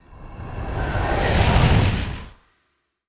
dark_wind_growls_04.wav